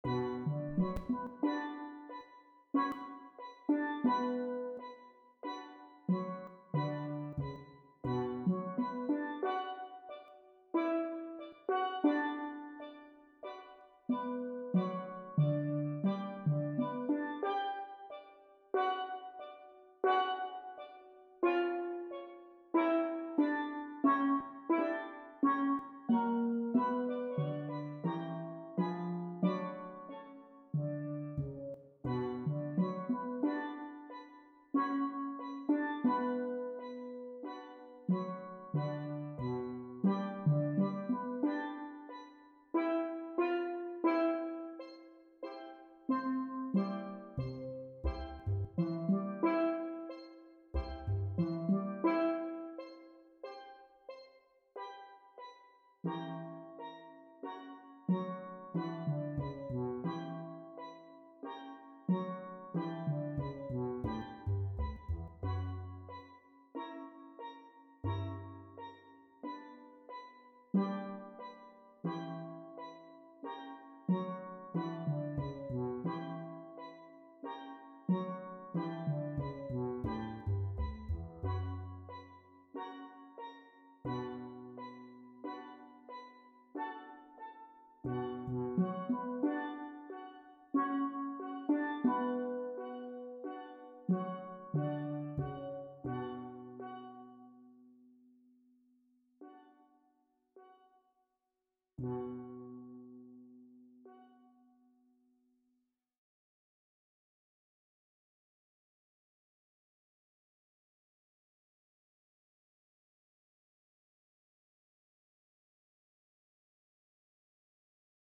Voicing: Steel Drum